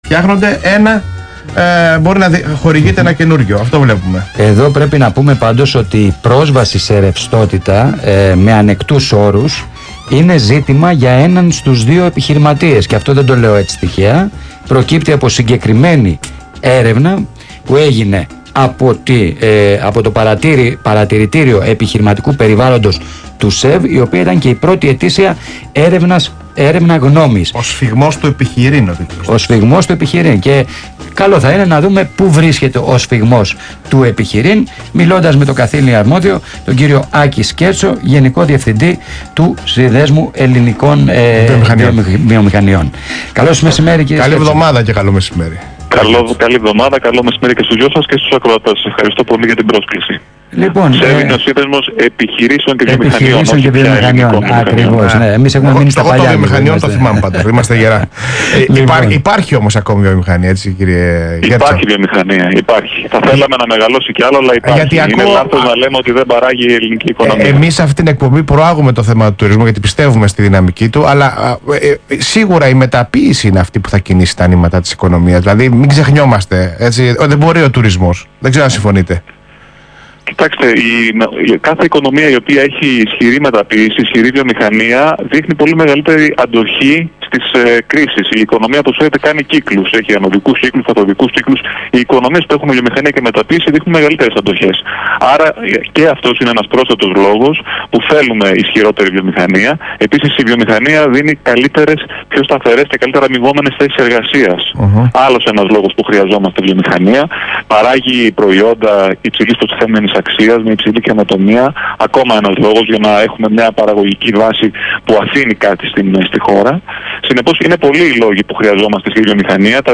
Συνέντευξη του Γενικού Διευθυντή του ΣΕΒ, κ. Άκη Σκέρτσου στον Ρ/Σ Alpha Radio σχετικά με την έρευνα "Ο σφυγμός του επιχειρείν", 10/7/2017